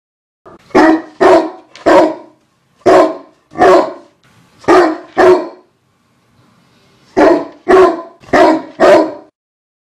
German Shepherd Dog Bark